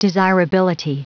Prononciation du mot desirability en anglais (fichier audio)
Prononciation du mot : desirability